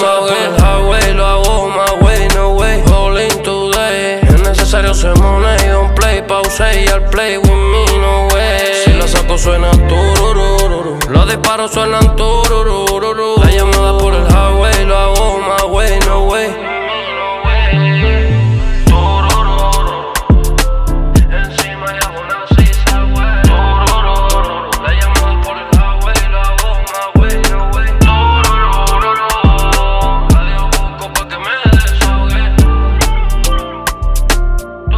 пробивного бита и лаконичного сэмпла
Hip-Hop Rap
Жанр: Хип-Хоп / Рэп